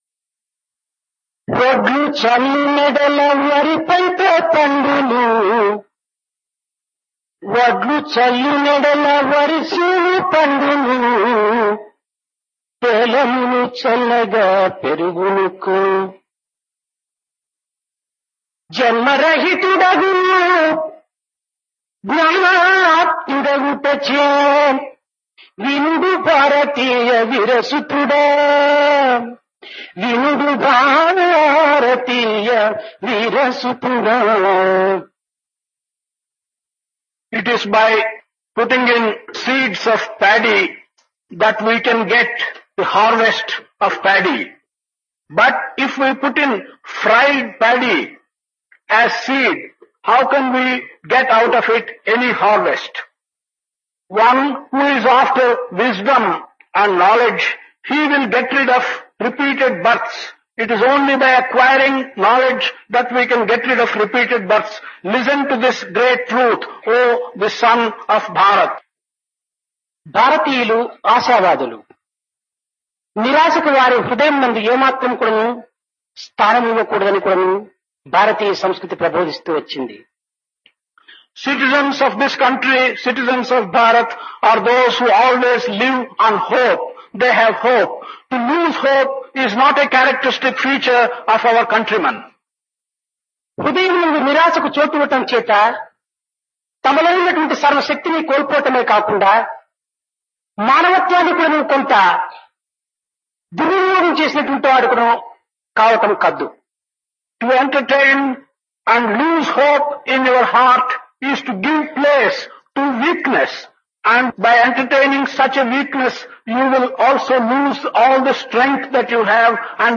Divine Discourse of Bhagawan Sri Sathya Sai Baba, Summer Showers 1974
Discourse